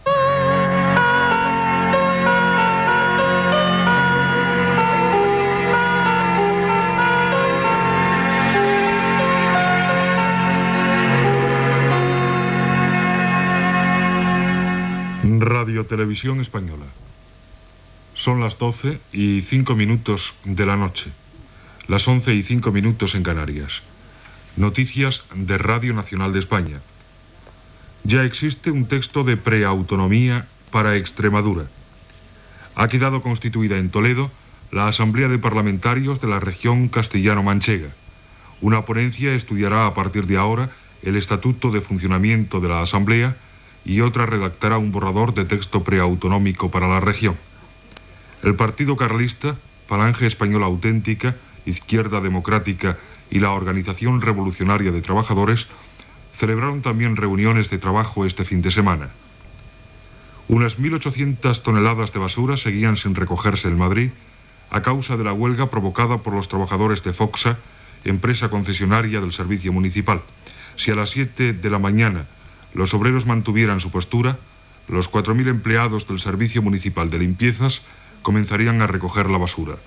Indicatiu, hora i les notícies de la mitjanit. Text de preautonomia per a Extremadura (aprovat el dia 14 de març de 1978) i vaga dels escombriaires de Madrid.
Informatiu